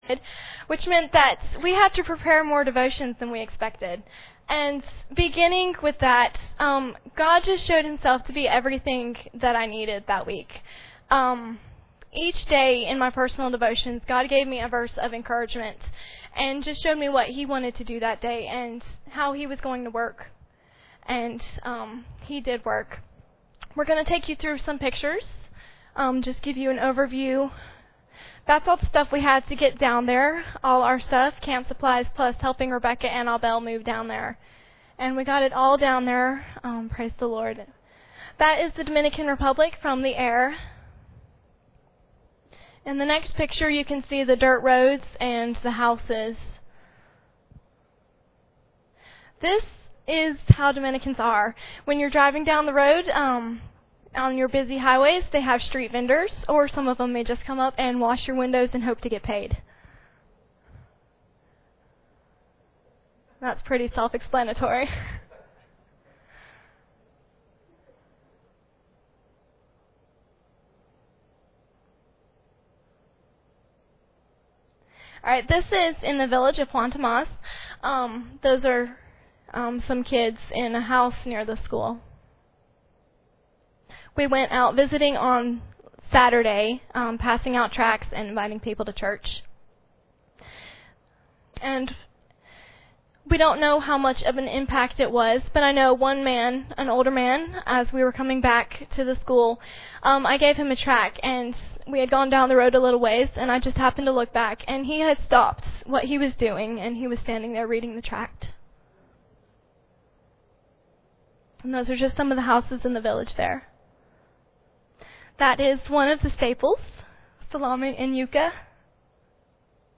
Dominican Republic Mission Team Testimonies 2012
Preacher: CCBC Members | Series: General